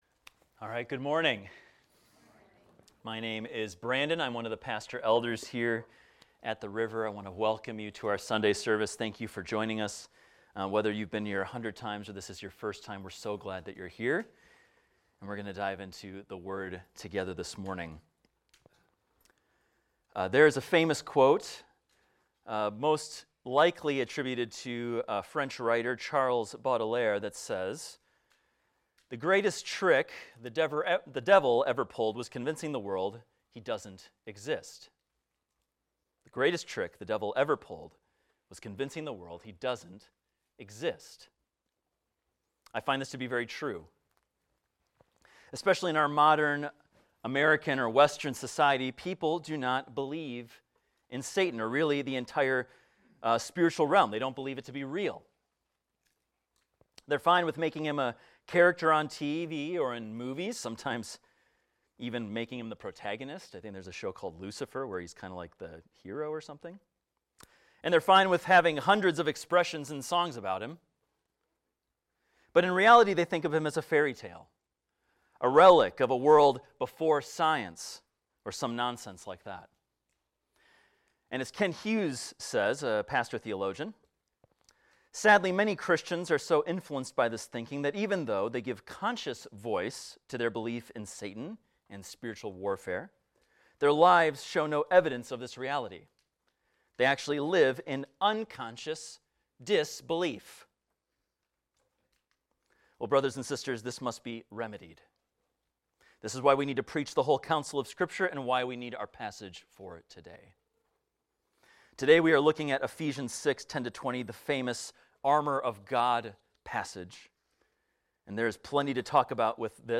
This is a recording of a sermon titled, "The Invisible War."